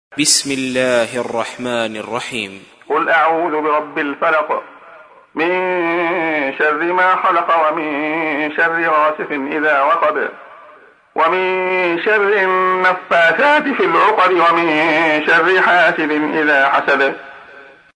تحميل : 113. سورة الفلق / القارئ عبد الله خياط / القرآن الكريم / موقع يا حسين